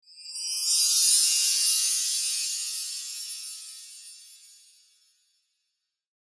Magical Bright Sparkling Transition Sound Effect
Bright, sparkling, and magical transition sound effect with a whimsical chime and ethereal shimmer. It works perfectly for fantasy film reveals, fairy dust animations, and positive UI transitions.
Magical-bright-sparkling-transition-sound-effect.mp3